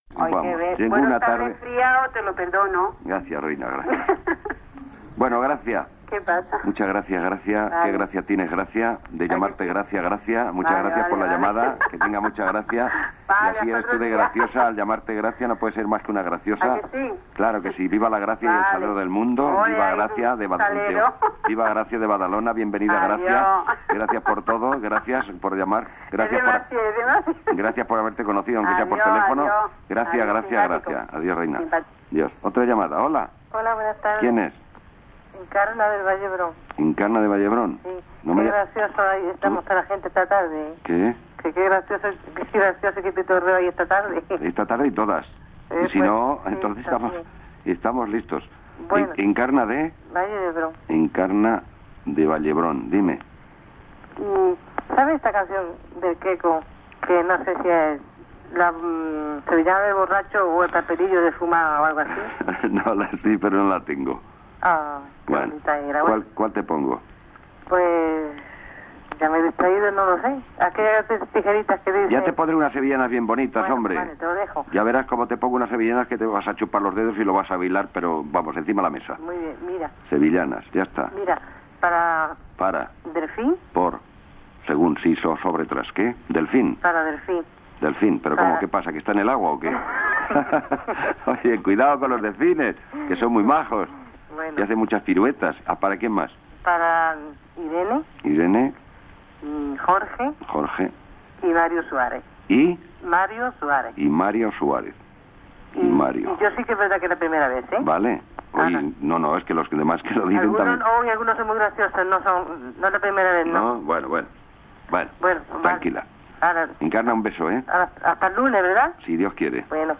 Peticions musicals dels oients per telèfon.